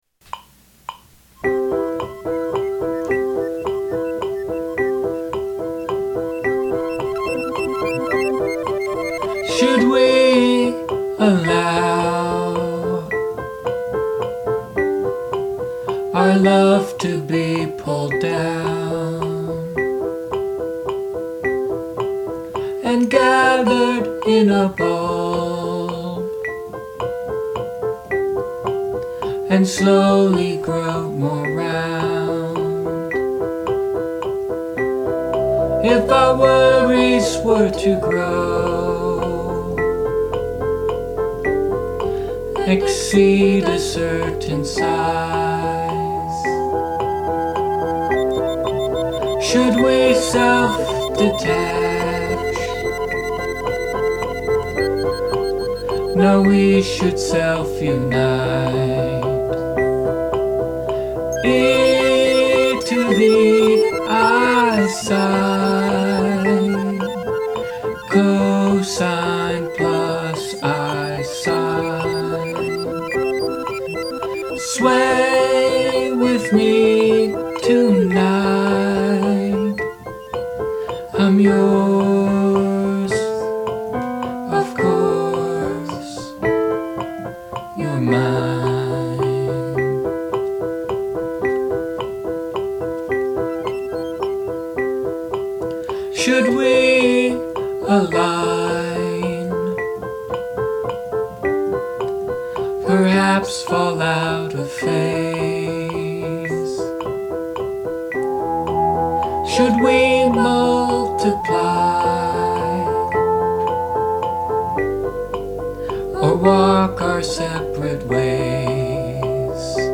C, F, G
verse, verse, chorus, verse, verse, chorus
I like the relatively quick waltz feel and the metronome, I really like the twittering synth in the background.
I think you guys are getting really good at singing together, too.
I like the way the last line of the chorus is stretched out over twice as many measures as you'd expect, and I like the little ascending piano line underneath that.